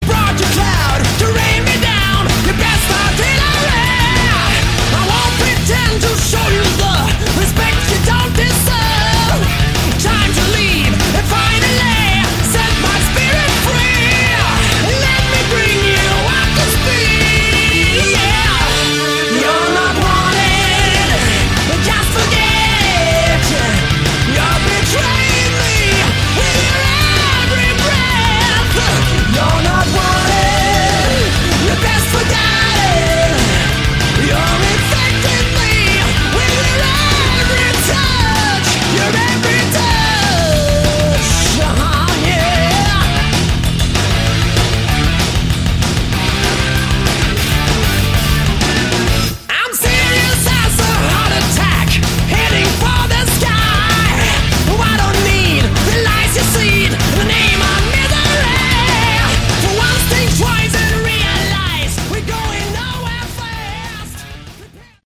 Category: Melodic Rock
guitars
organ, keyboards
drums
bass
vocals